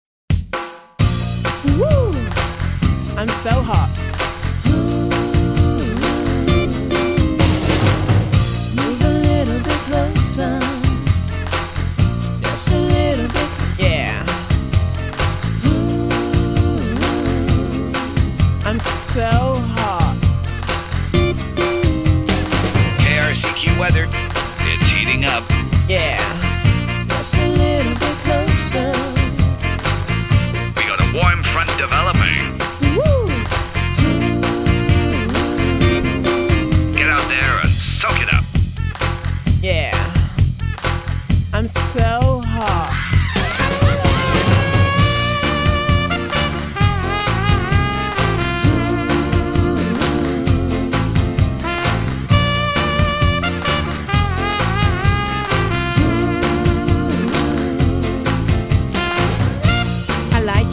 Pop
приятная, чувственная музыка